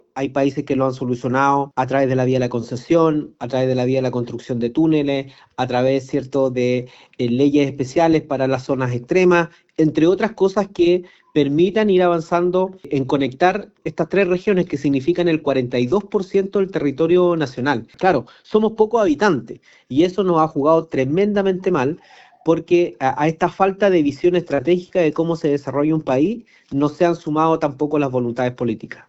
Sobre esa iniciativa se refirió el gobernador de Aysén, Marcelo Santana, indicando que se requiere voluntad política para solucionar este problema que afecta al transporte del sur del país.